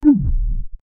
cartoonish bounce
cartoonish-bounce-73wq25uy.wav